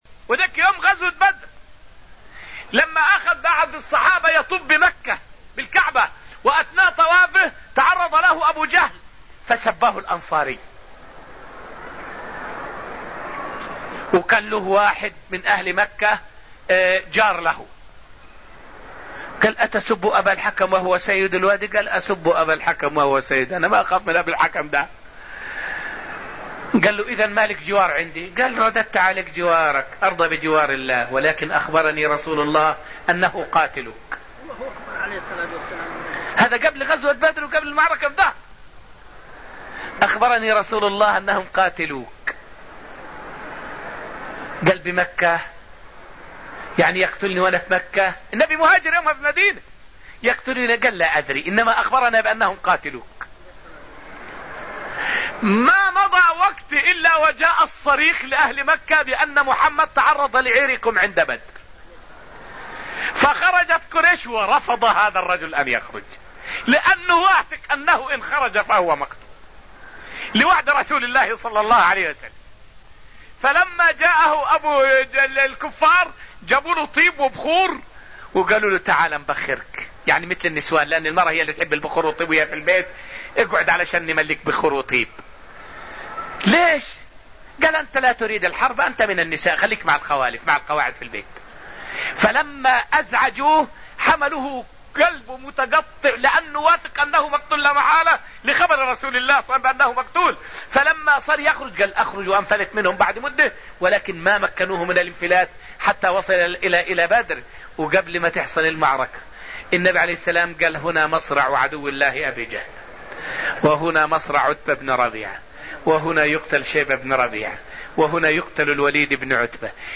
فائدة من الدرس الثالث عشر من دروس تفسير سورة النجم والتي ألقيت في المسجد النبوي الشريف حول صدق نبوة النبي محمد عليه الصلاة والسلام بمهلك أبي جهل وغيره من الكفار.